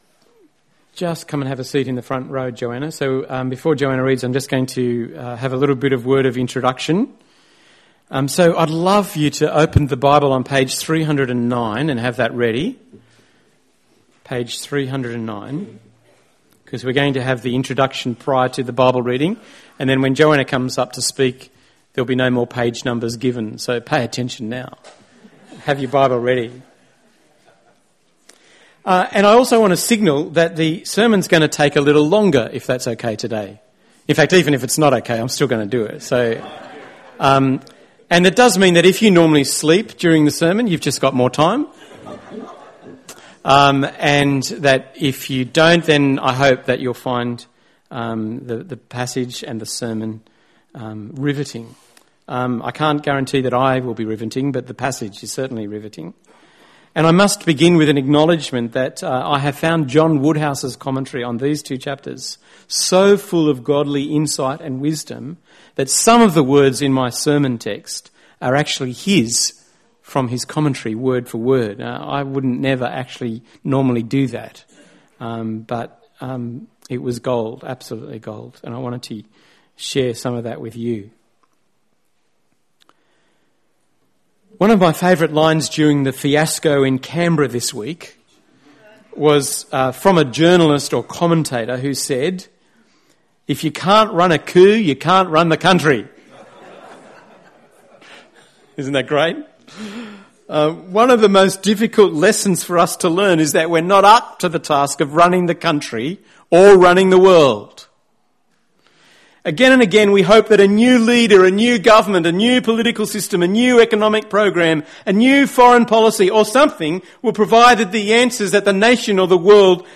The fourth sermon in our series “In search of a King II” from 2 Samuel. Today’s passage: 2 Samuel 11-12. Audio recorded at our Tewantin service.